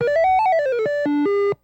Video Game Synth You Lost Sound